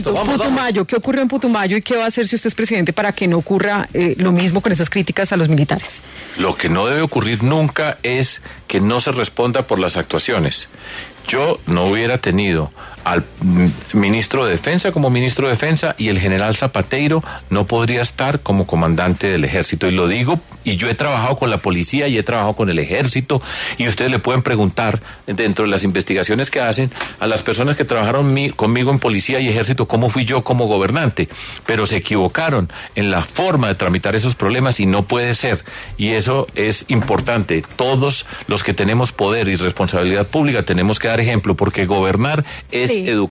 En entrevista con Sigue La W, el candidato presidencial de la Coalición Centro Esperanza, Sergio Fajardo, se refirió a la polémica operación del Ejército Nacional en Putumayo, por la cual, el ministro de Defensa, Diego Molano, fue citado a un debate de moción de censura en la Cámara de Representantes.